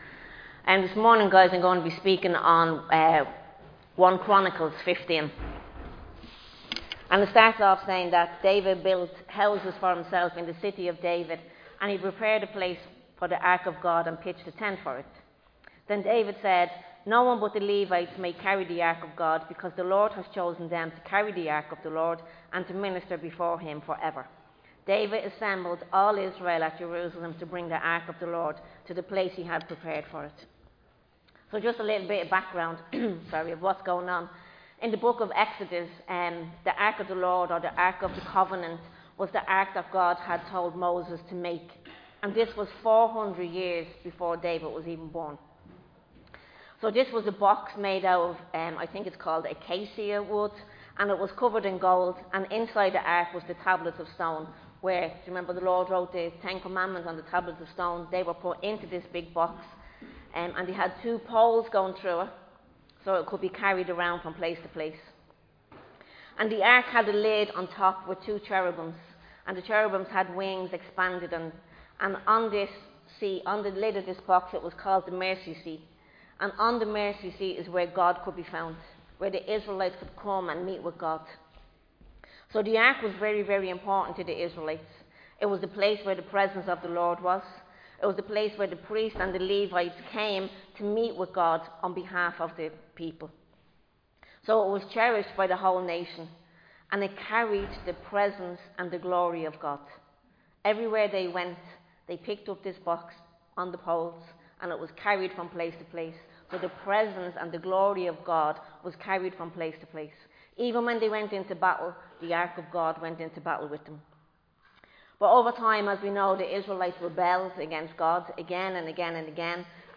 Recorded live in Liberty Church on 8 June 2025